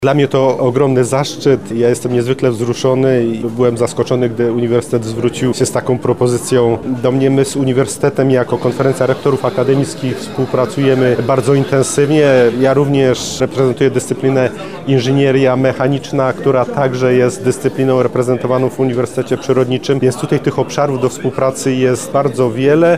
Uroczystość odbyła się w Centrum Kongresowym uczelni z udziałem władz akademickich, przedstawicieli świata nauki i gości zagranicznych.